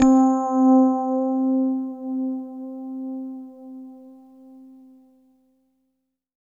20 RHOD C4-L.wav